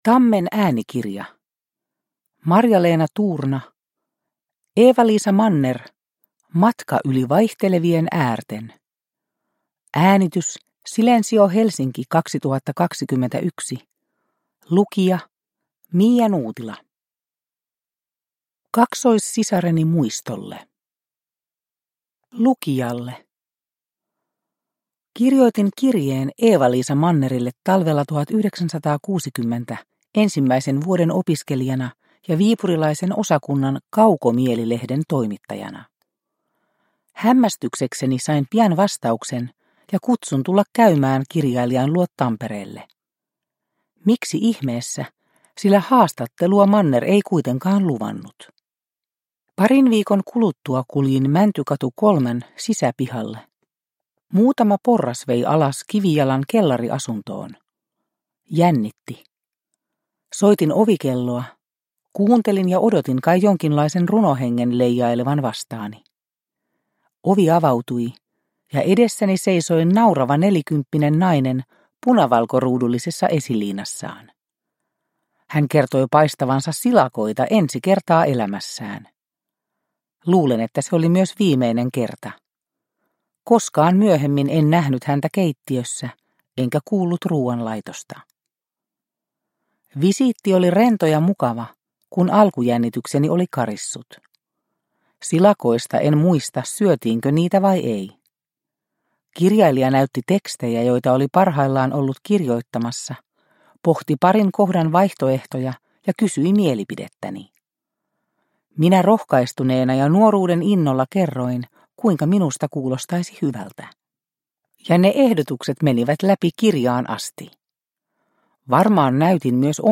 Eeva-Liisa Manner – Ljudbok – Laddas ner